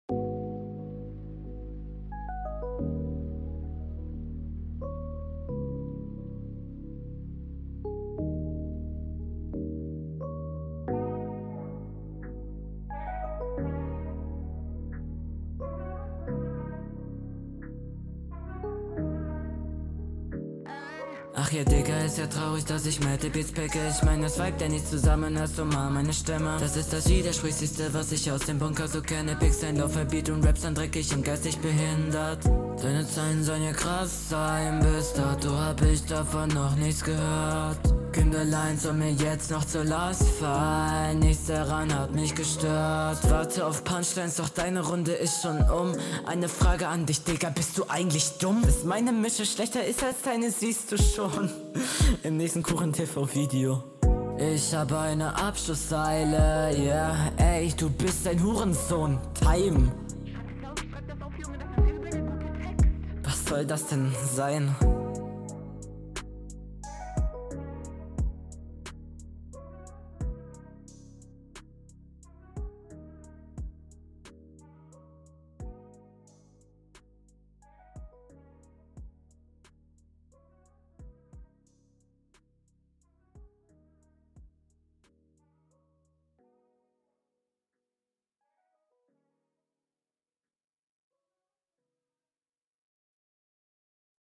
Flow: Flow auf jeden Fall besser als bei deinem Gegner.
gefällt mir tatsächlich vom klang besser, die s-laute natürlich teilweise kritisch, aber feier den sound.